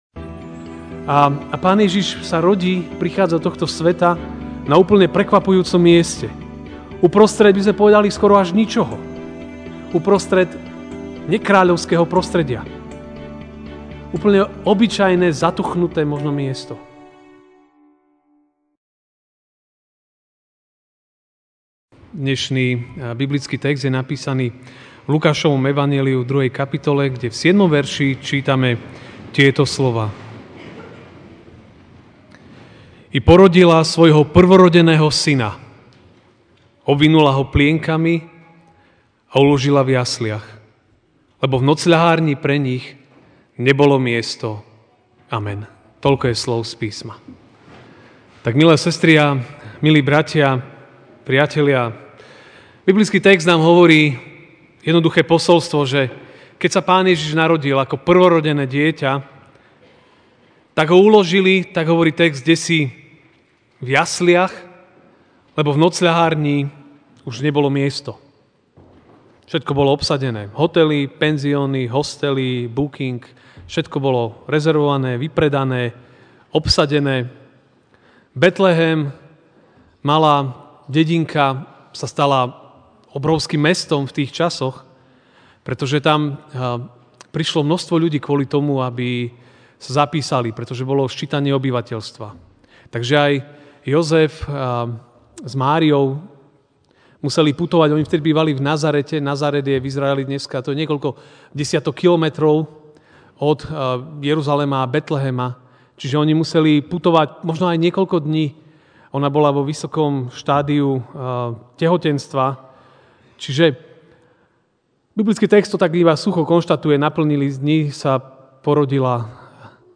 dec 24, 2018 Miesto Pána Ježiša v našom živote MP3 SUBSCRIBE on iTunes(Podcast) Notes Sermons in this Series Štedrovečerná kázeň: Miesto Pána Ježiša v našom živote (L 2, 7) I porodila svojho prvorodeného syna, obvinula Ho plienkami a uložila v jasliach, lebo v nocľahárni pre nich nebolo miesto.